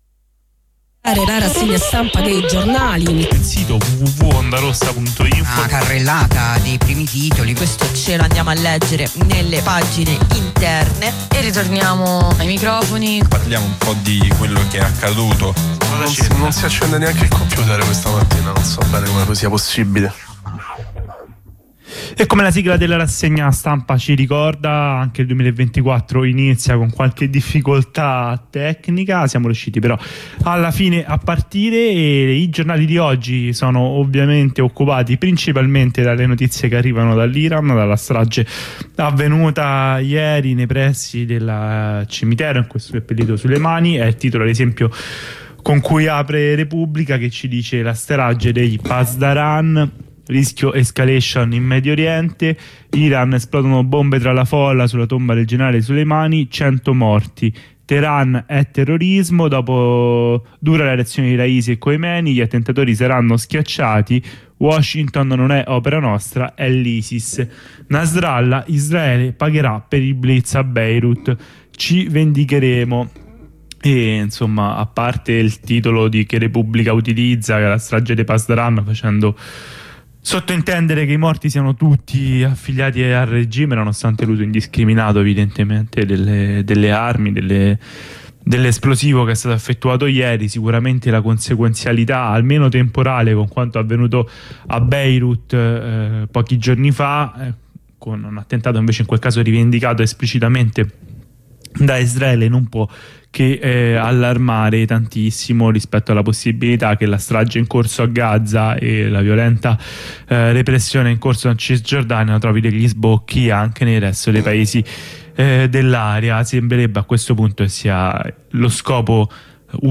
la lettura dei quotidiani di oggi sugli 87.9 di Radio Ondarossa